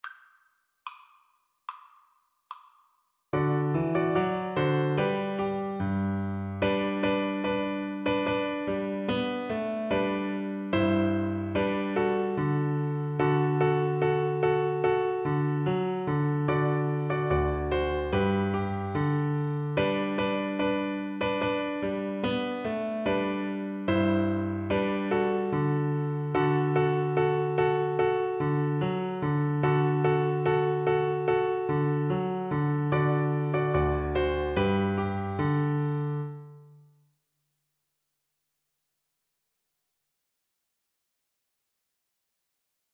A traditional British playground song
Arrangement for Voice
4/4 (View more 4/4 Music)
Traditional (View more Traditional Voice Music)